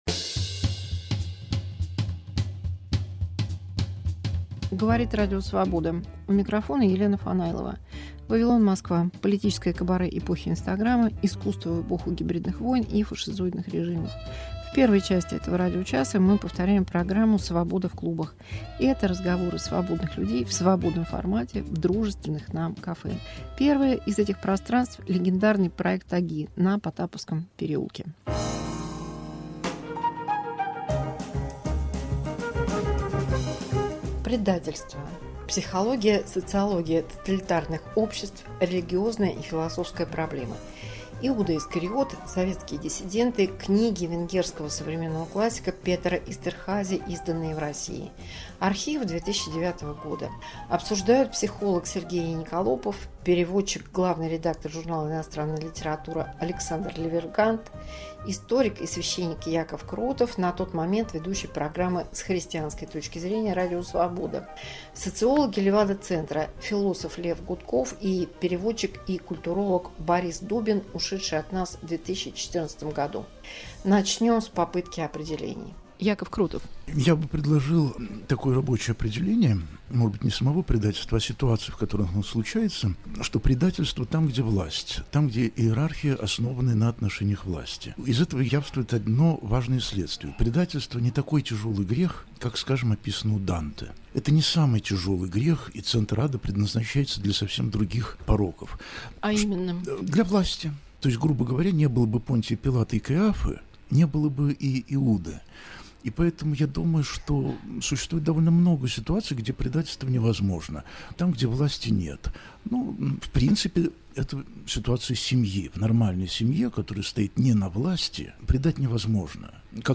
Предательство как личная, социальная и религиозная проблема. Архив 2009 с психологом, священником и социологами